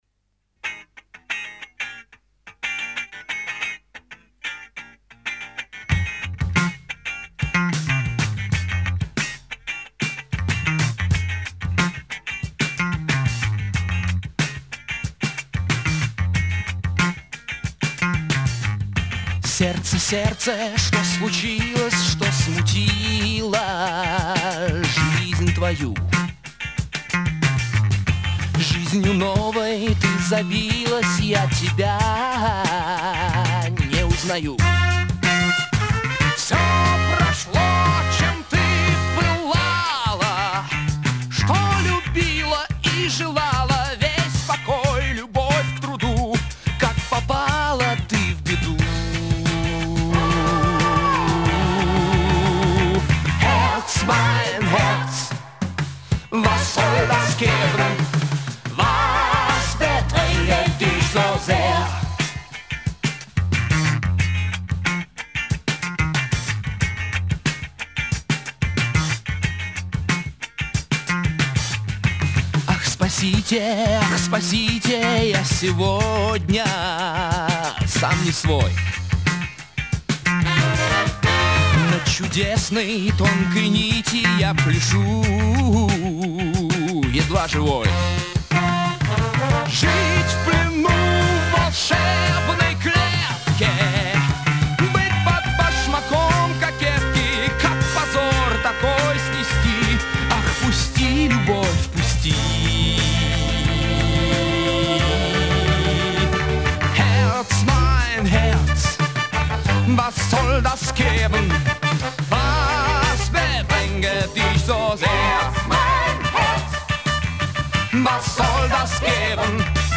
он выпускает концептуальный альбом в стиле арт-рок.